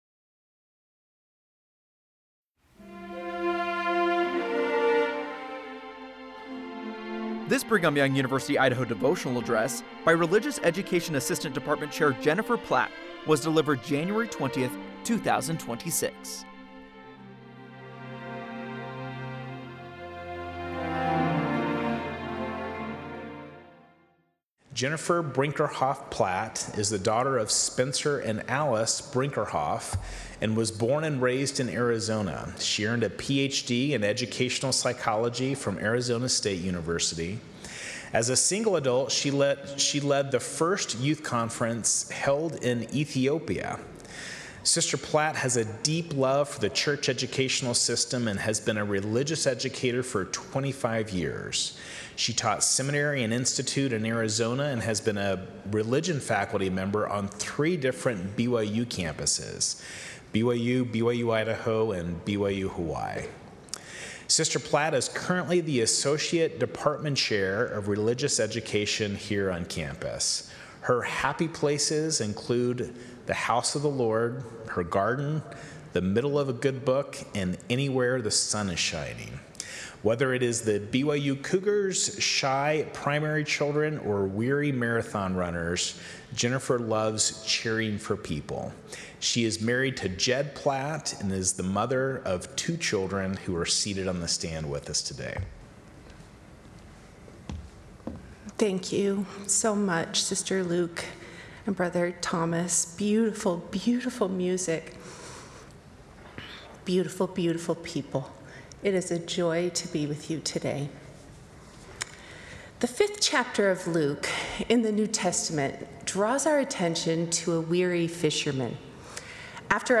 Devotional address